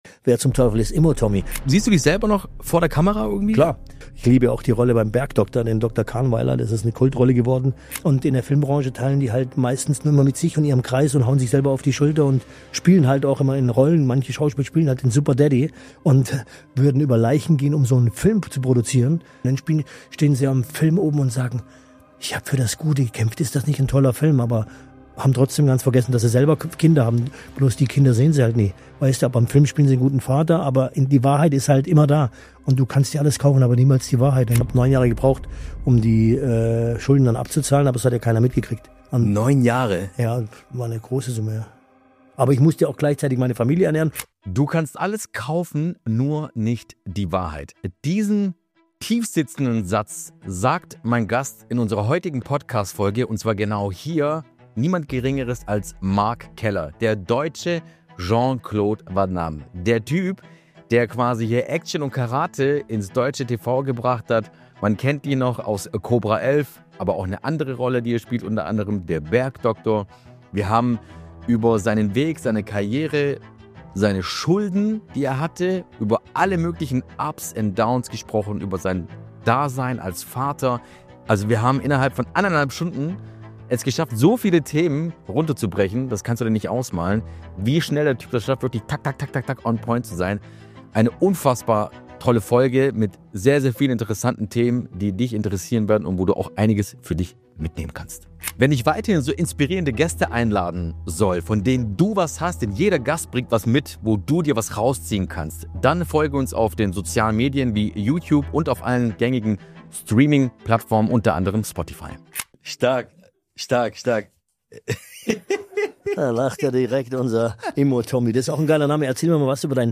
Ein Gespräch über Absturz, Verrat und die Realität hinter dem Erfolg.